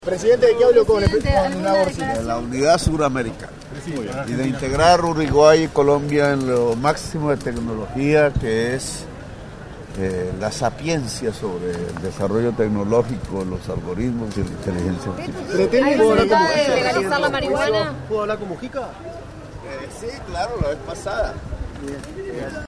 Declaraciones a la prensa del presidente de Colombia, Gustavo Petro
Tras el encuentro, Petro realizó declaraciones a la prensa.